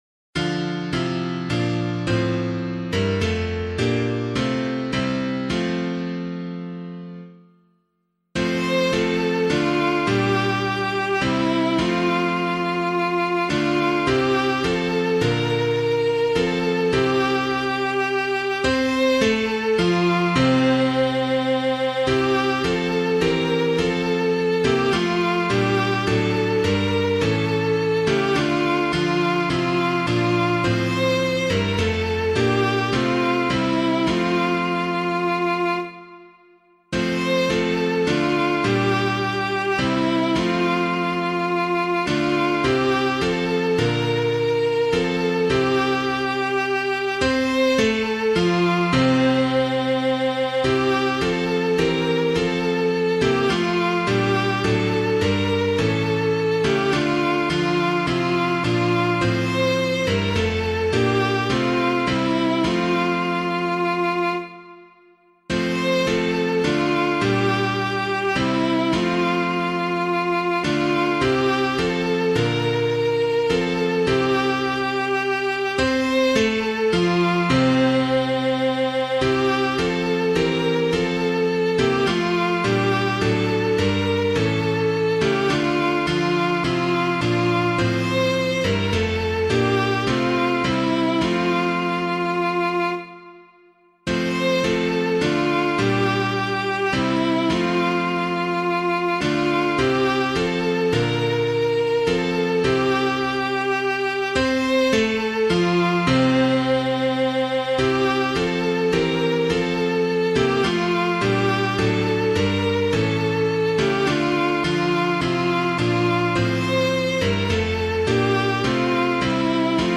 Public domain hymn suitable for Catholic liturgy.
Come Thou Almighty King [anonymous - MOSCOW] - piano.mp3